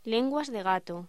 Locución: Lenguas de gato
voz